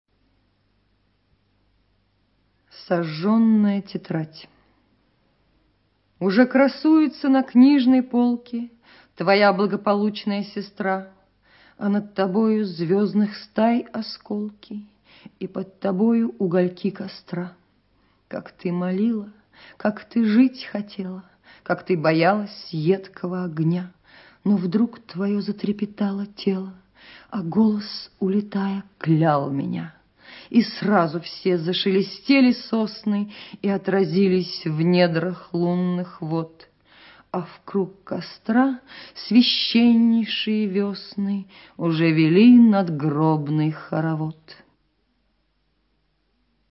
2. «Анна Ахматова Сожженная тетрадь – ( Читает Маргарита Терехова)» /